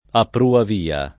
[ a ppruav & a ]